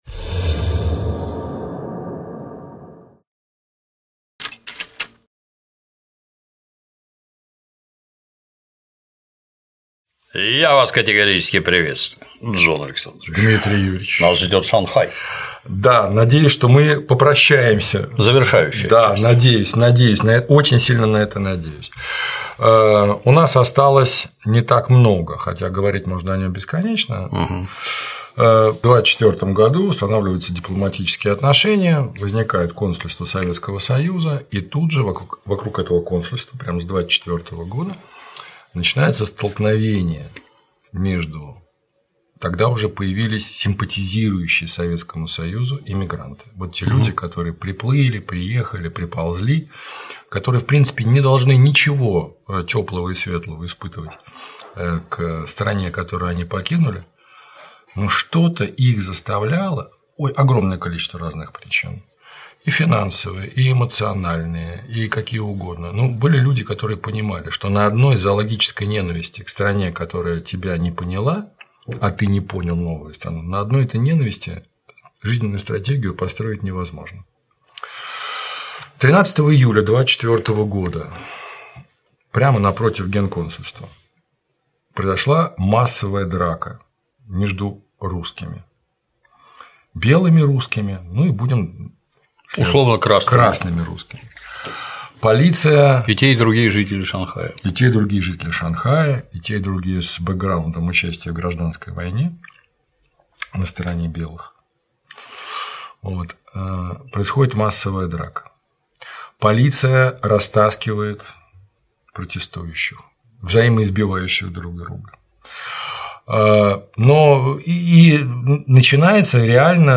interview_shanghai5.mp3